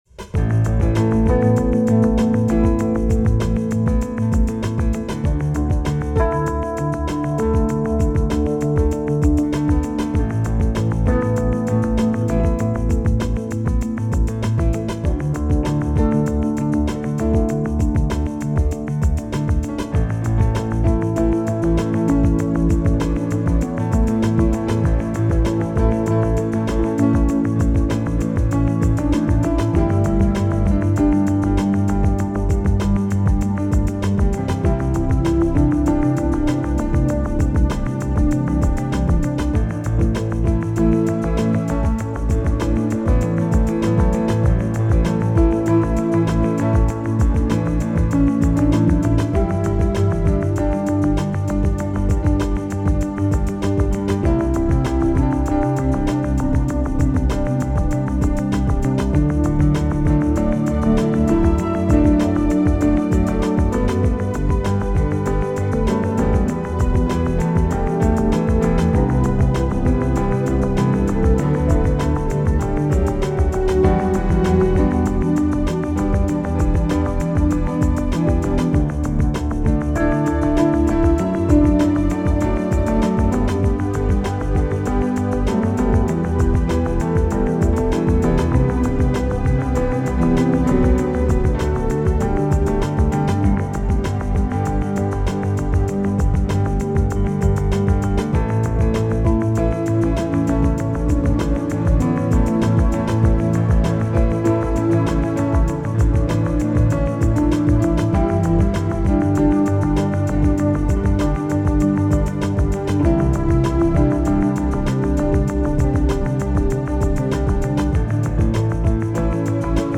The only thing this song is good for is hold music.